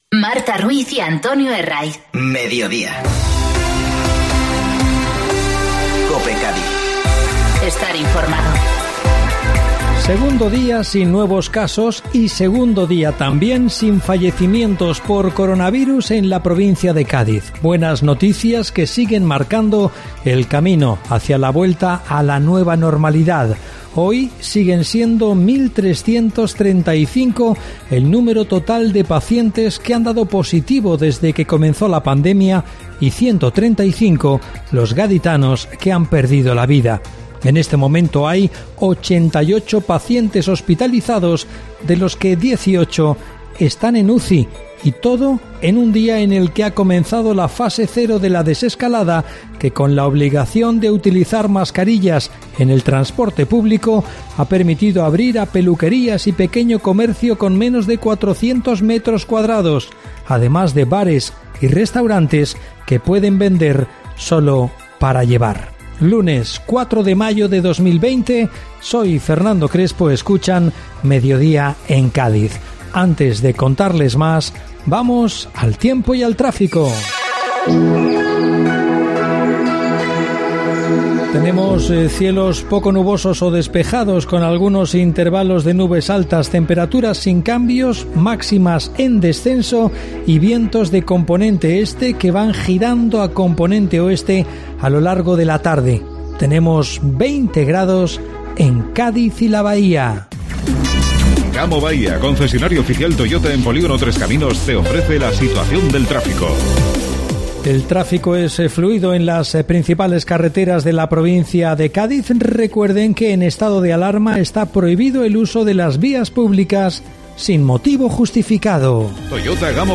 Informativo Mediodía COPE Cádiz (4-5-2020)